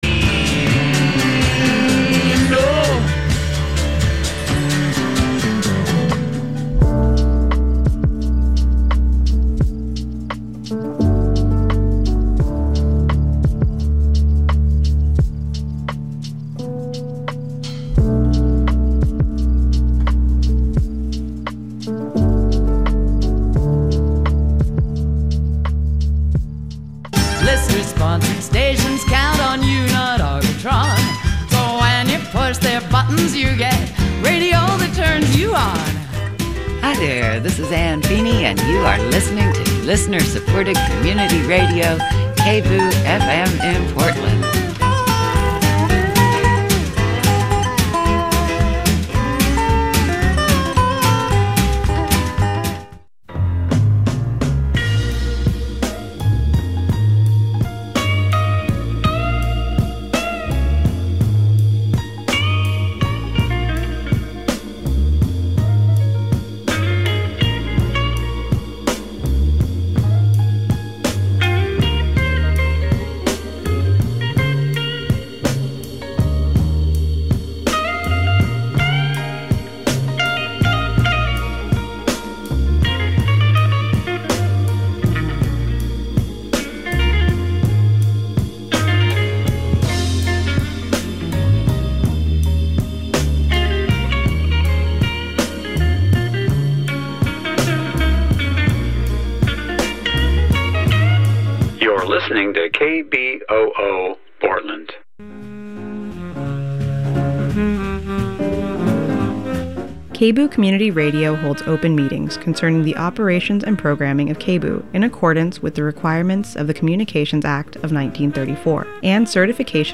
INVESTIGATIVE News Radio